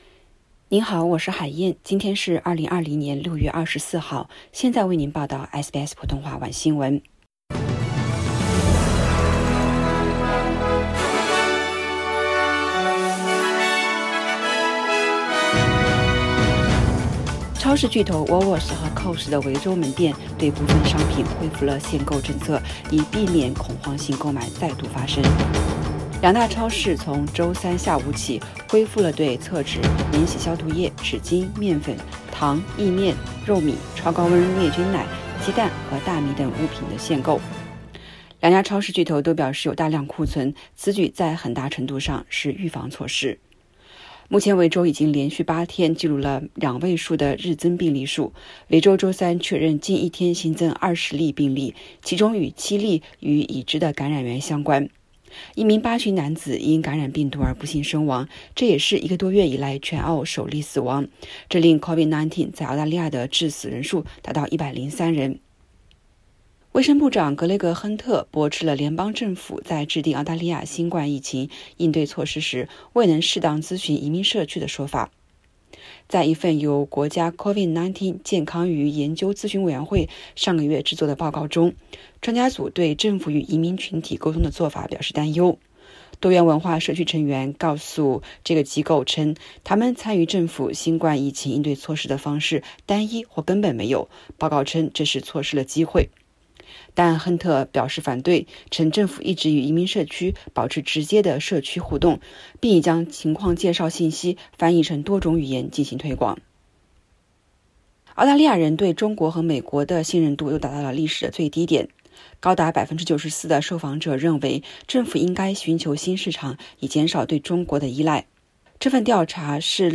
SBS晚新闻（6月24日）